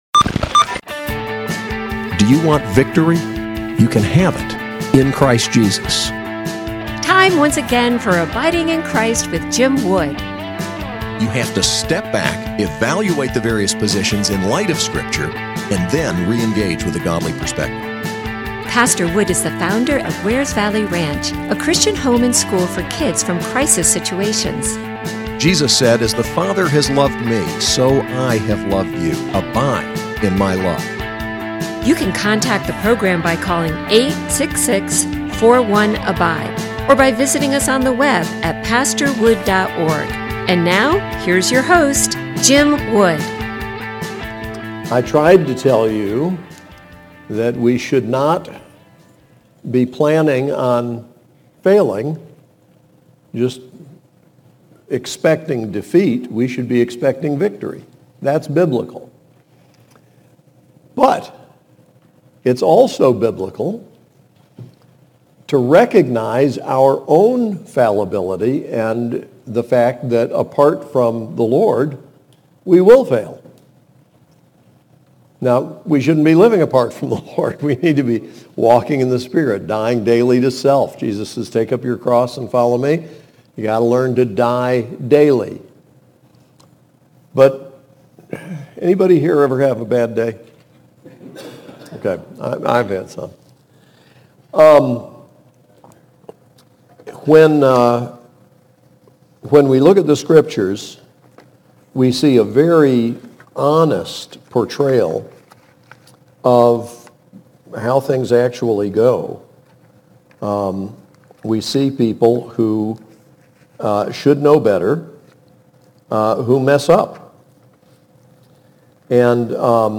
Series: COA Men's Retreat